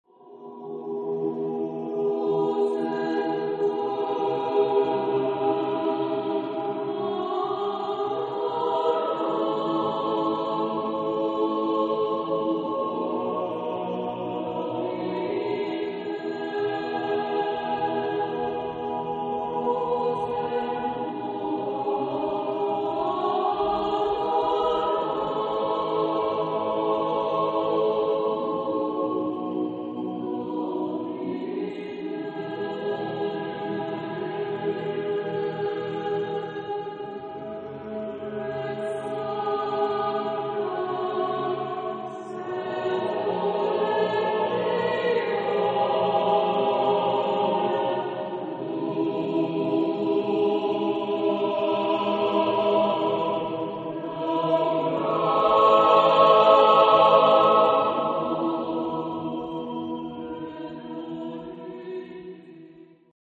Genre-Style-Forme : Motet ; Sacré
Type de choeur : SSAATTBB  (8 voix mixtes )
Tonalité : atonal